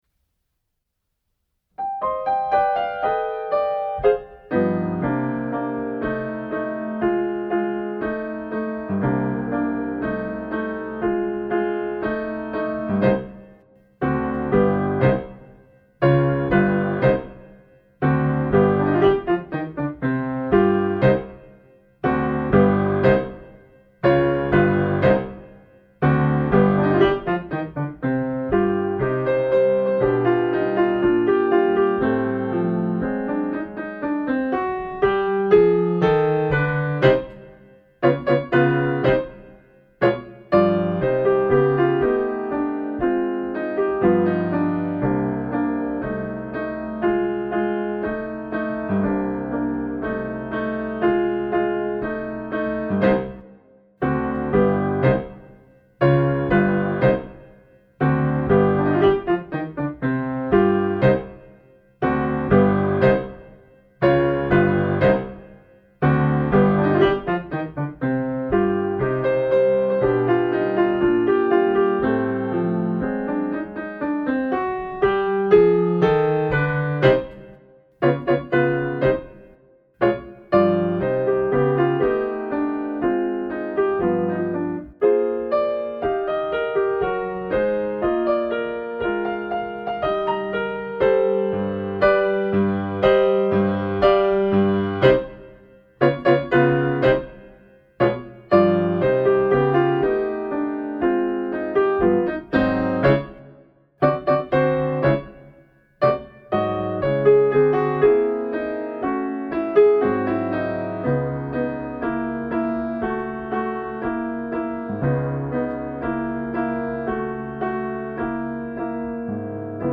【Instrumental / リマスター版2025】 mp3 DL ♪